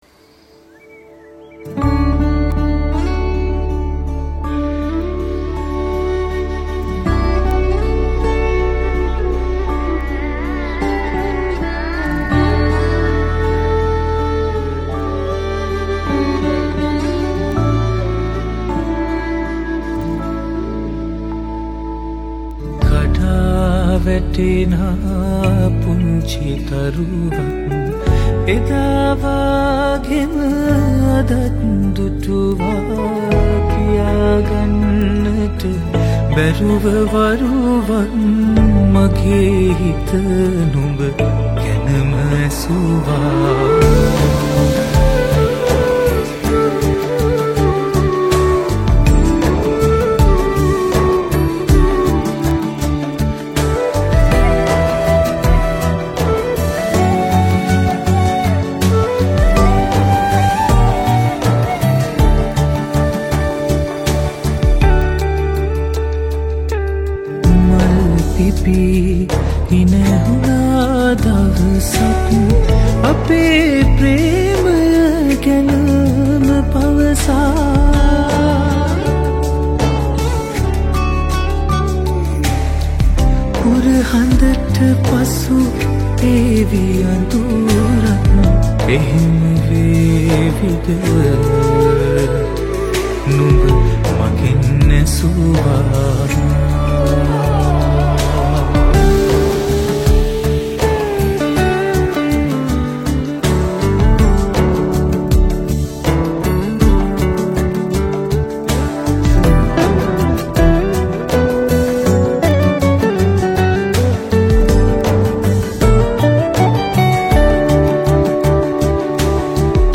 Vocals
Guitar
Flute